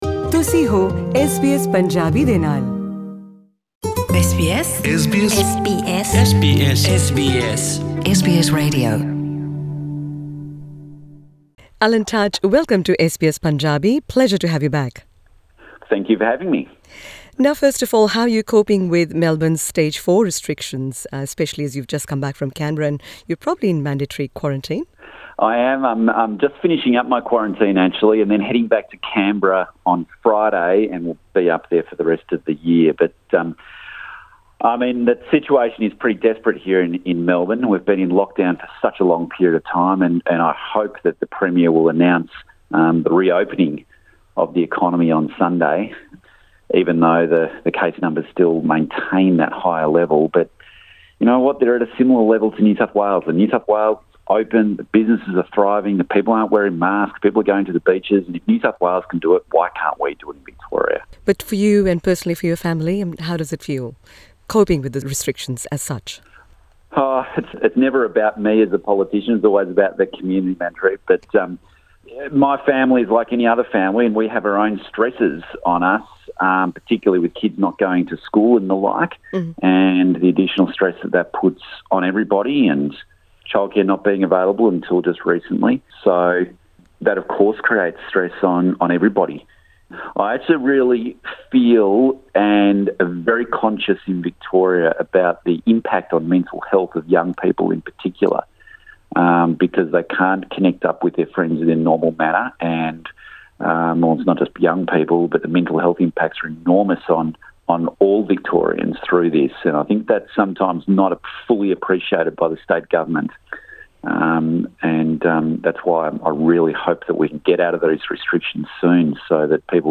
ਐੱਸ ਬੀ ਐਸ ਪੰਜਾਬੀ ਨਾਲ ਕੀਤੀ ਇੱਕ ਖ਼ਾਸ ਇੰਟਰਵਿਊ ਦੌਰਾਨ ਕਾਰਜਕਾਰੀ ਇਮੀਗ੍ਰੇਸ਼ਨ ਮੰਤਰੀ ਐਲਨ ਟੱਜ ਨੇ ਕਿਹਾ ਕਿ ਅੰਤਰਰਾਸ਼ਟਰੀ ਸਰਹੱਦਾਂ ਦੁਬਾਰਾ ਖੋਲ੍ਹਣ ਲਈ ਕੋਵੀਡ-19 ਵੈਕਸੀਨ ਦੀ ਉਪਲਬਧਤਾ ਅਤੇ ਰਾਜਾਂ 'ਤੇ ਪ੍ਰਦੇਸ਼ਾਂ ਦੀ ਕੁਆਰੰਟੀਨ ਸਮਰੱਥਾ ਦੋਨੋ ਹੀ ਬਹੁਤ ਅਹਿਮ ਹਨ।